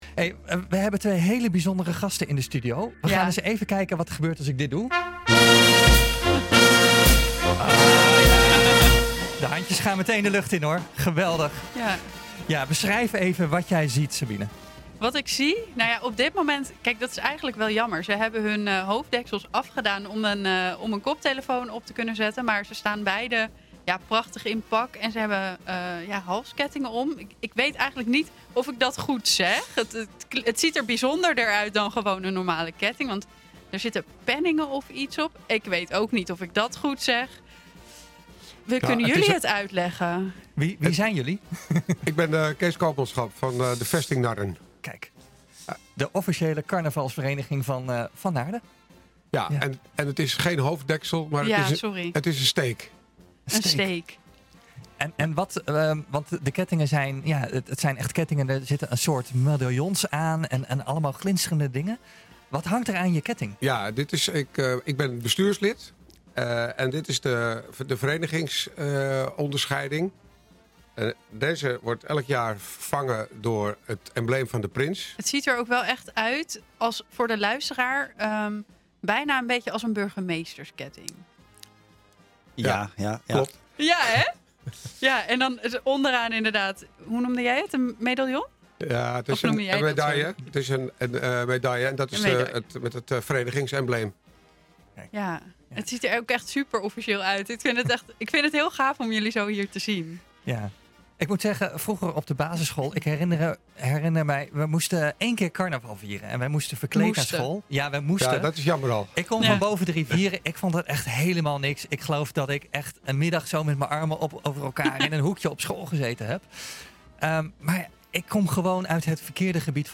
In radioprogramma De Lunchclub op NH Gooi Radio was afgelopen zaterdag een afvaardiging van de carnavalsvereniging te gast. Luister het gesprek terug via deze link.